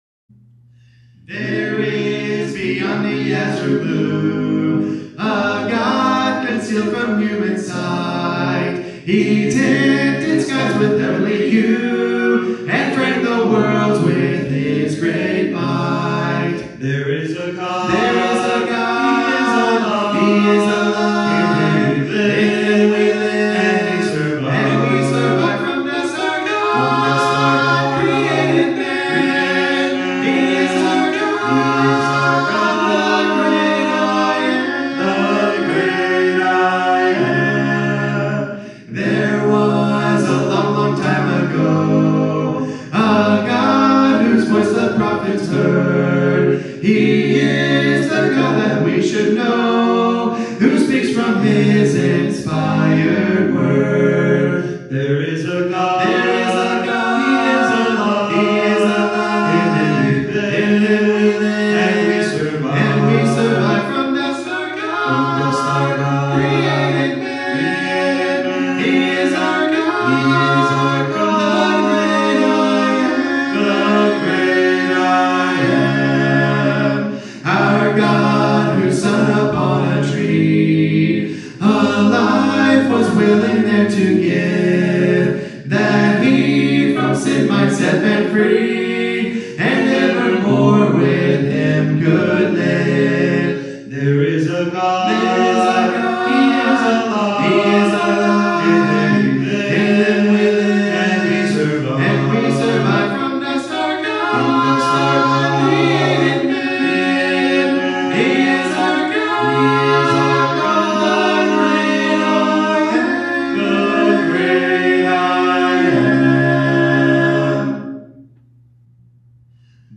Sunday Worship Service | Kanawha City Church of Christ
Due to technical difficulties with our video recordings, this week’s service will focus heavily on singing.